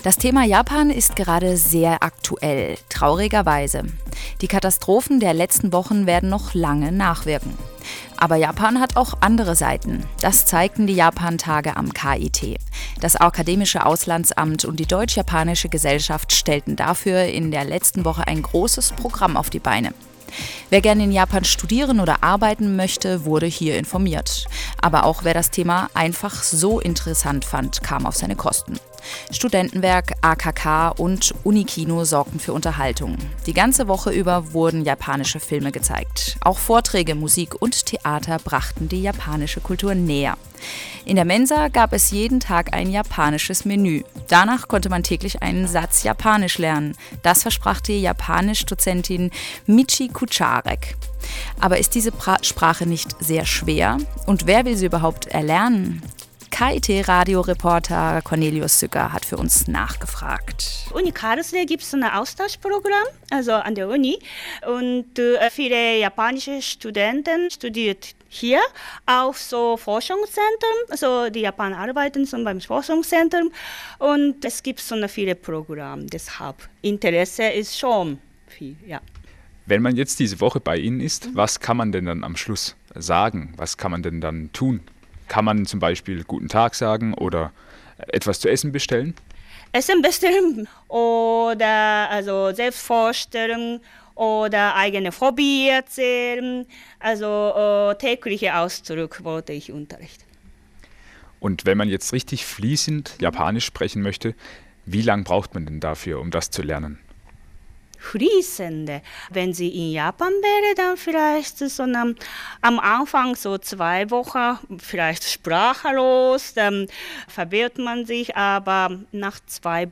Japanisch Lernen ist nicht schwer - Reportage von den Japantagenr : Beitrag bei Radio KIT am 12.05.2011